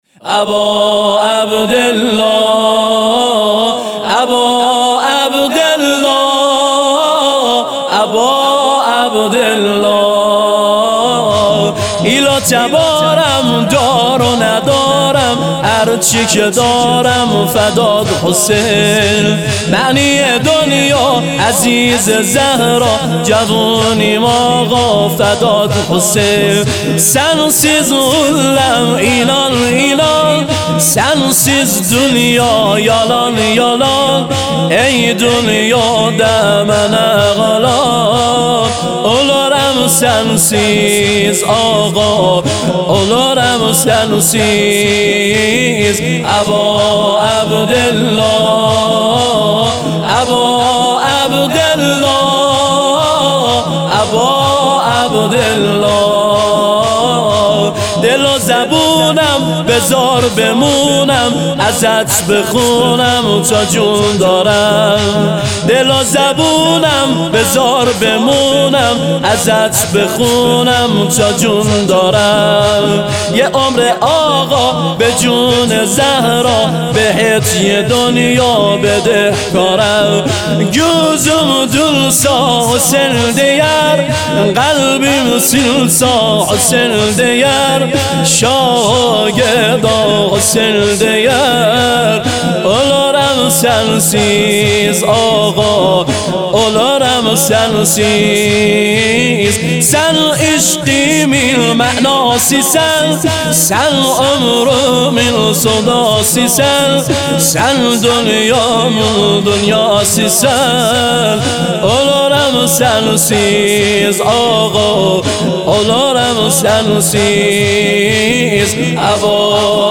نوحه زنجیر زنی ایل و تبارم دار و ندارم